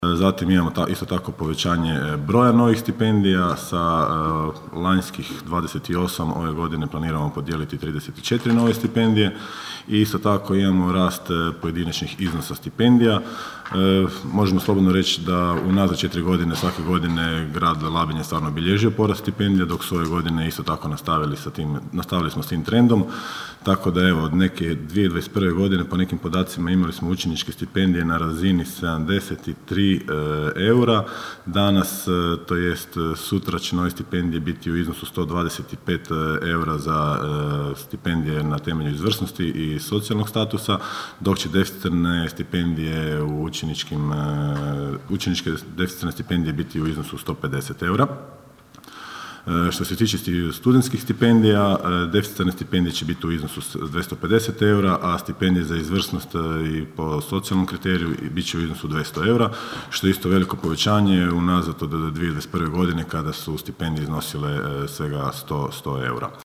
Zamjenik gradonačelnika Goran Vlačić posebno se osvrnuo na stipendije čiji je iznos s dosadašnjih 160 tisuća povećan na 200 tisuća eura: (